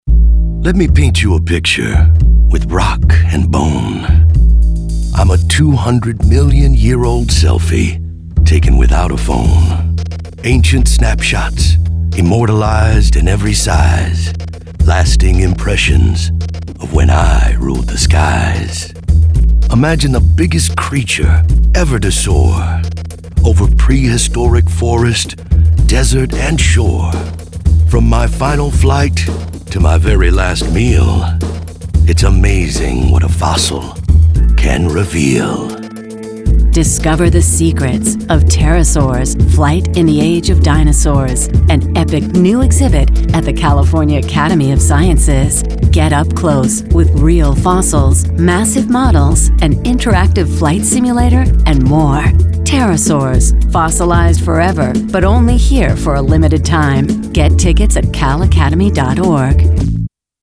RADIO: "SELF PORTRAIT"